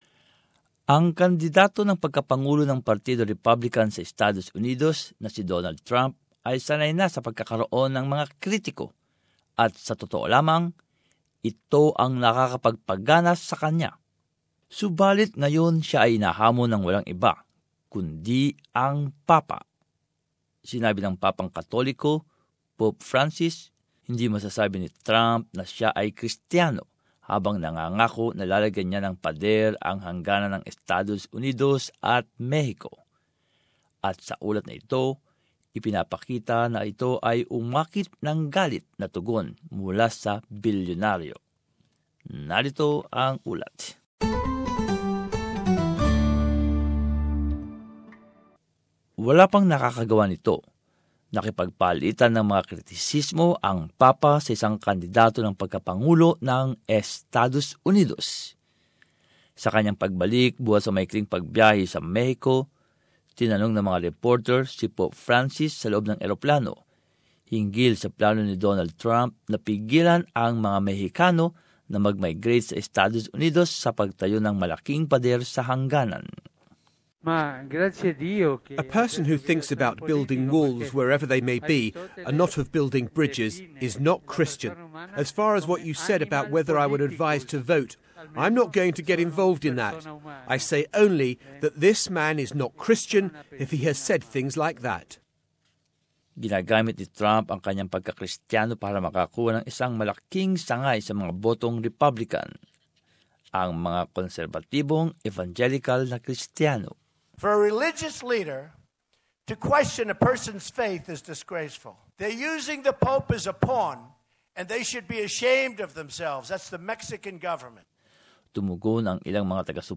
And as this report shows, it's drawn an angry response from the billionaire tycoon.